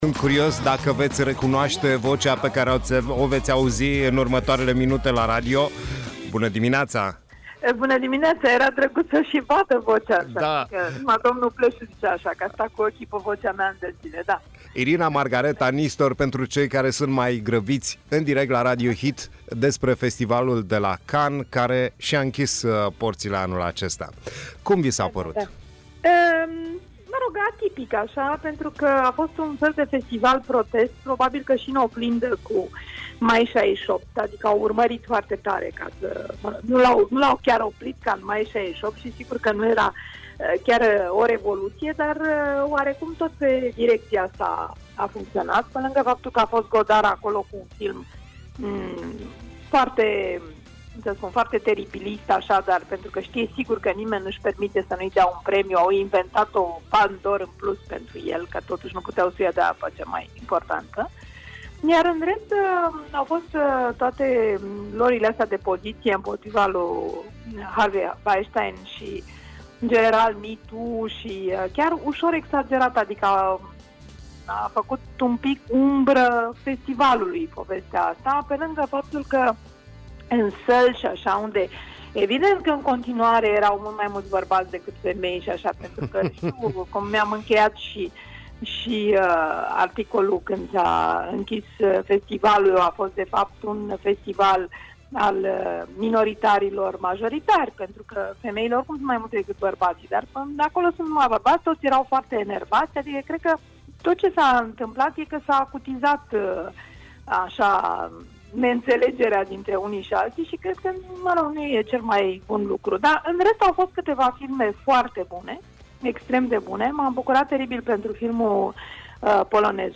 Cronică Cannes. Interviu cu Irina Margareta Nistor, critic de film